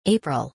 • April که تلفظ آن/eɪprəlˈ/ است و در فارسی آن را آوریل می‌گویند.